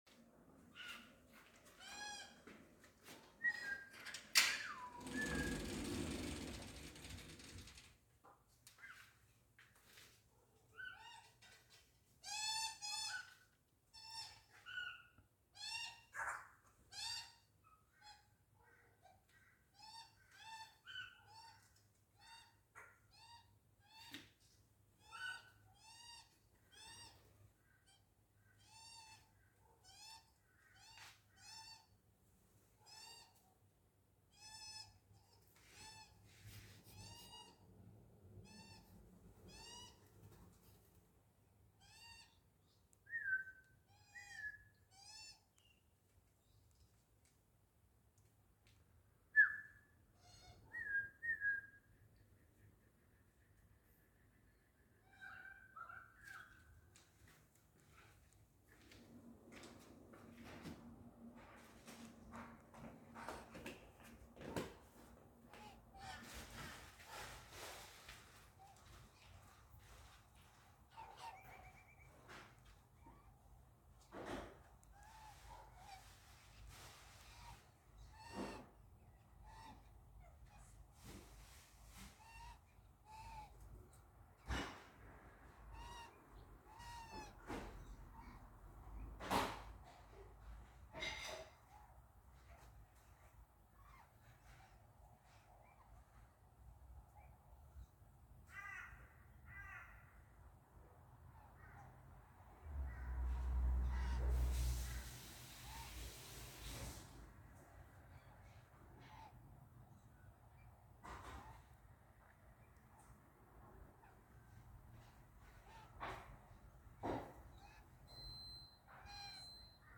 Feeding Butcher Birds and Juvenile Magpie